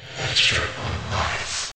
get_extra_life.ogg